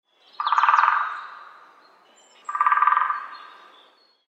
دانلود صدای دارکوب برای کودکان از ساعد نیوز با لینک مستقیم و کیفیت بالا
جلوه های صوتی
برچسب: دانلود آهنگ های افکت صوتی انسان و موجودات زنده